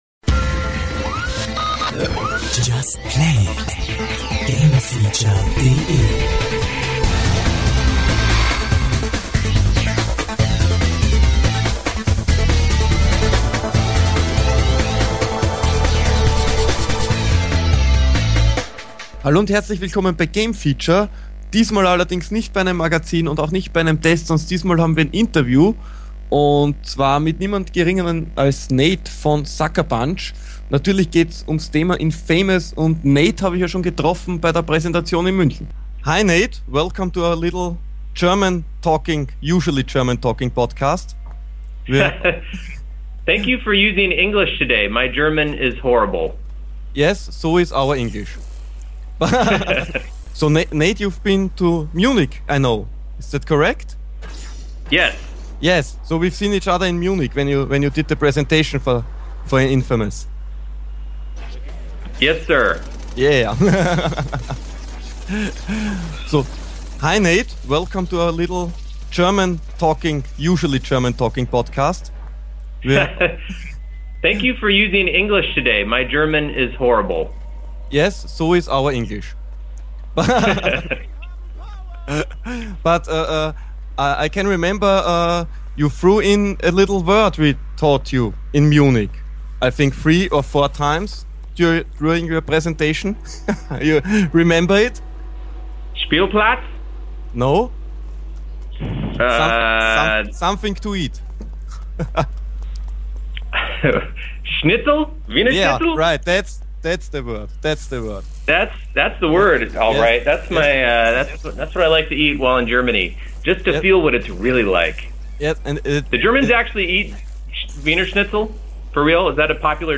Natürlich gab es auch einige Fragen die uns leider nicht beantwortet werden durften, aber trotz alledem haben wir jede Menge erfahren und sehr viel zu lachen gehabt. Selbst wenn mein Englisch in den letzten Jahren merklich etwas gelitten hat.